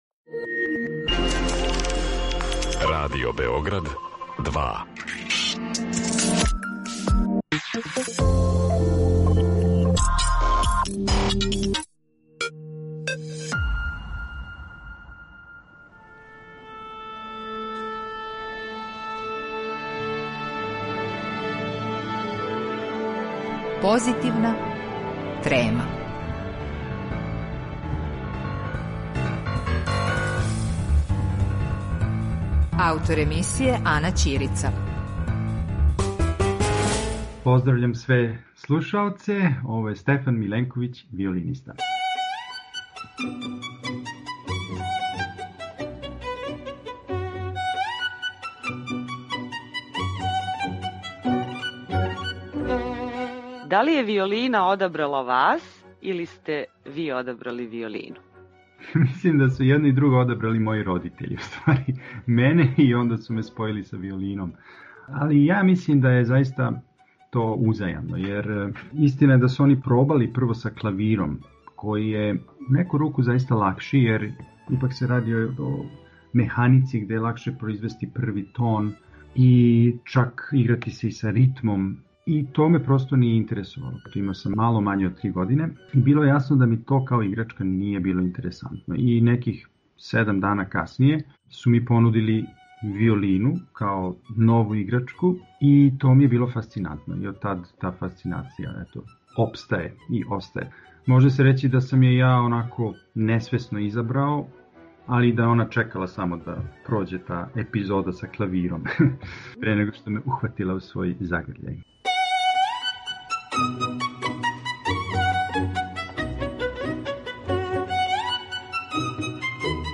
Разговарамо са јединственим уметником продуктивне и дуготрајне сценске присутности, високог професионализма и изузетне креативности. Његова уметничка филозофија и начин живота представљају спој истраживања општељудског музичког наслеђа и искуства, што се огледа и у стварању истинске везе са публиком и приређивању привлачних и енергичних наступа.